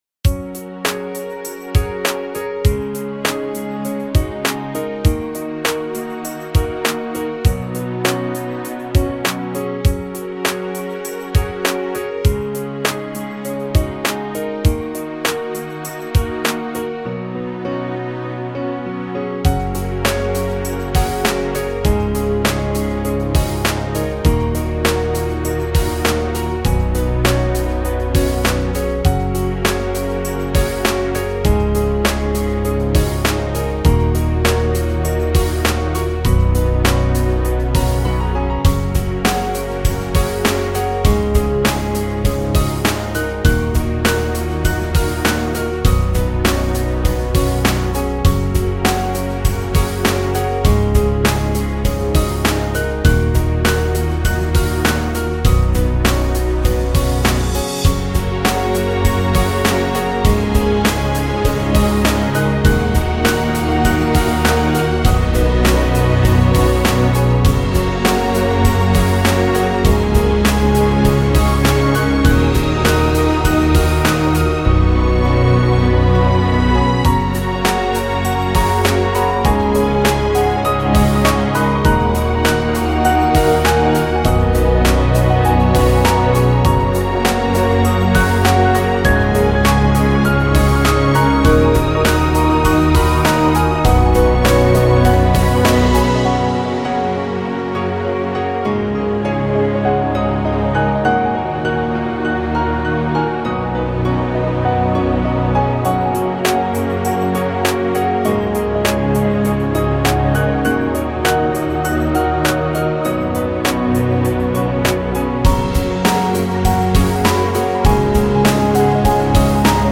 影视原声